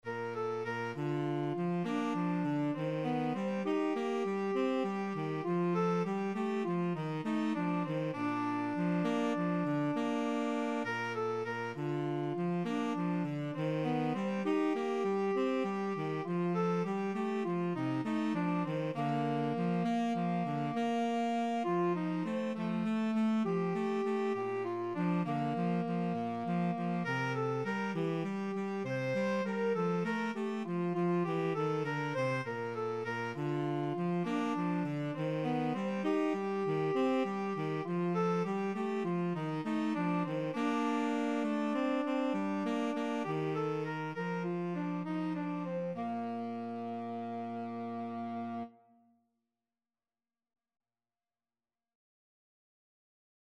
Tenor Sax 1Tenor Sax 2
9/8 (View more 9/8 Music)
Moderato